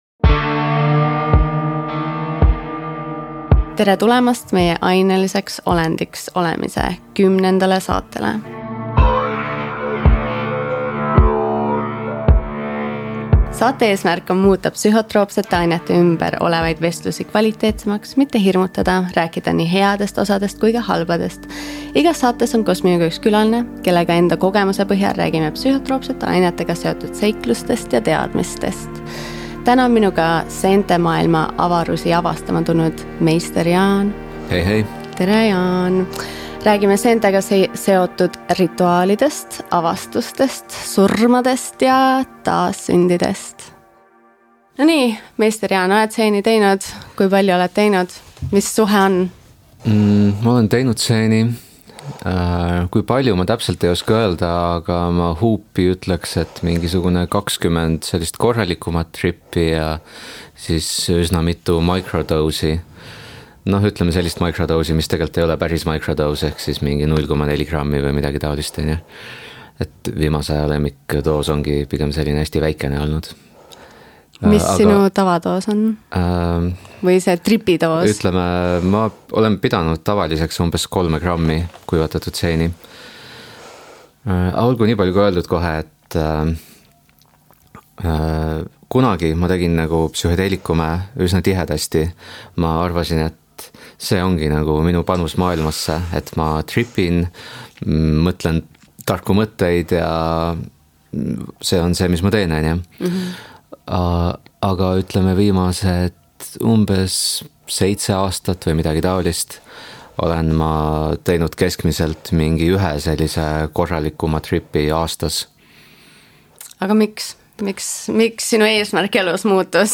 Juttu tuleb sellestki, kuidas psilotsübiini sisaldavaid seeni kasvatatakse ning mis on nende potentsiaal ja ohud inimeste ravimisel. See on vestlus, kus ükski lugu pole tabu.